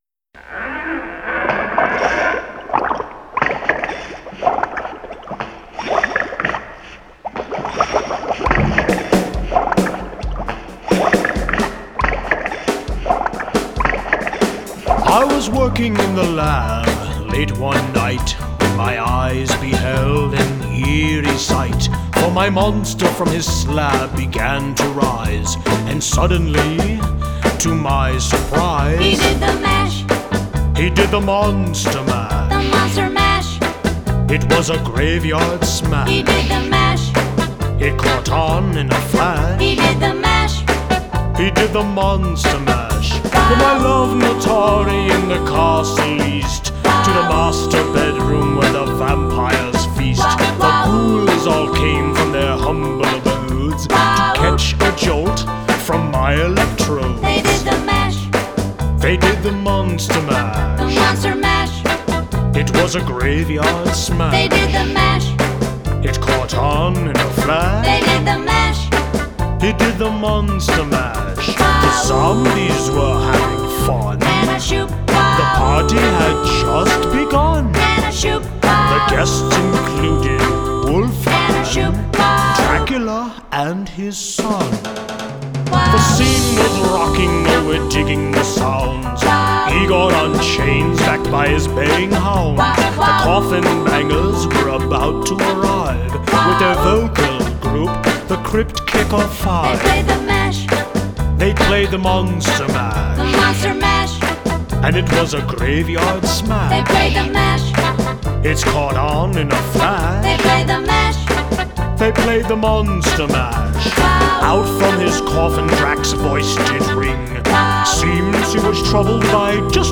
KARAOKE ACCOMPANIMENT